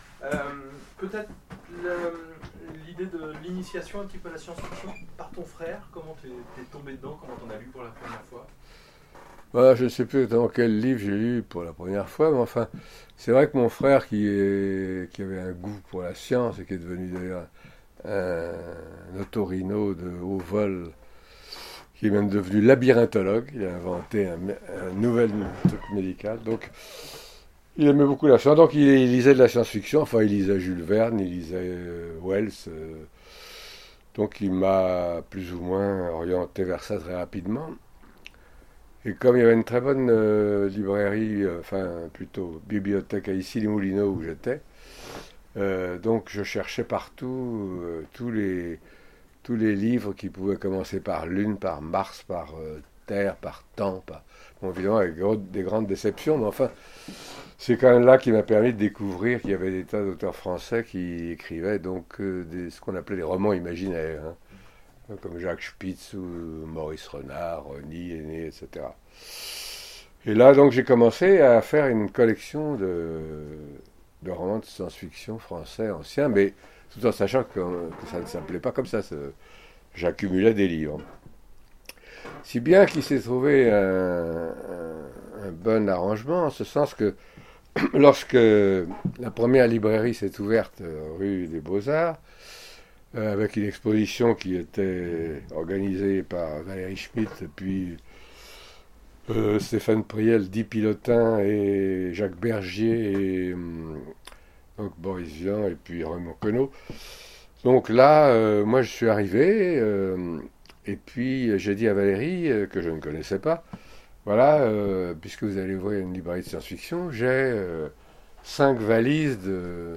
Interview 2017 : Philippe Curval pour On est bien seul dans l’univers
Rencontre avec un auteur